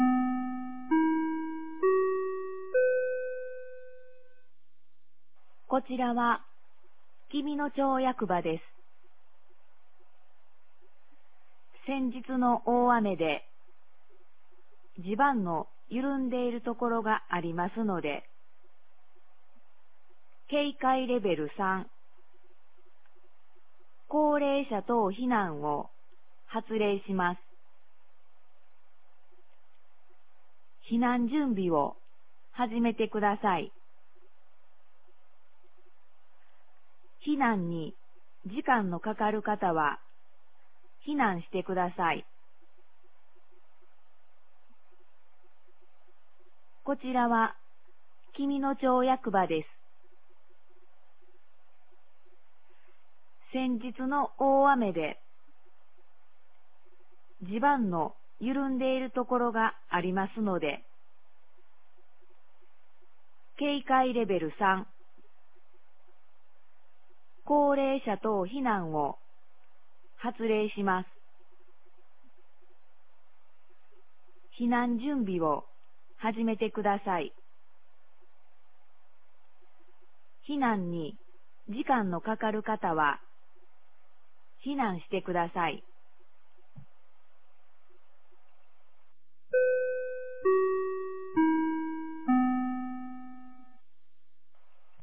2023年06月08日 15時01分に、紀美野町より全地区へ放送がありました。